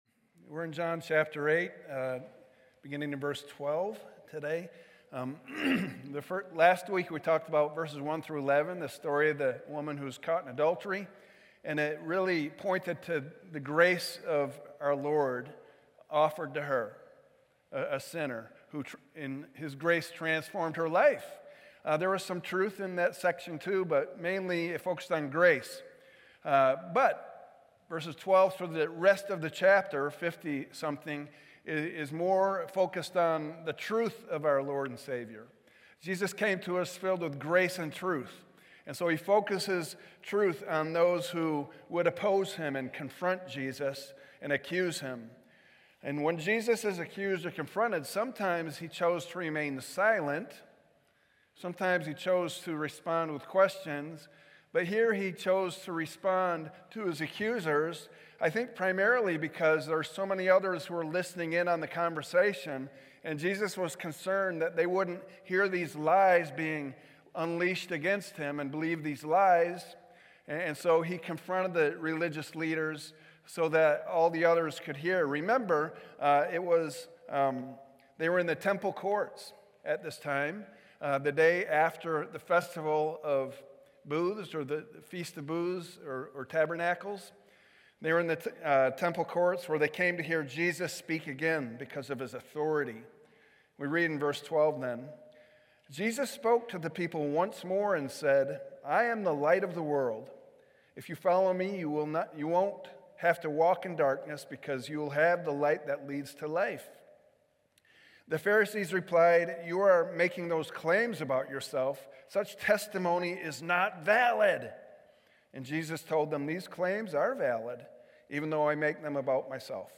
Sermons | Countryside Covenant Church